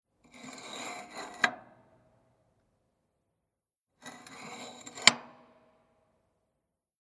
Opening and closing the ventilation wheel
0578_Lueftungsrad_oeffnen_und_schliessen.mp3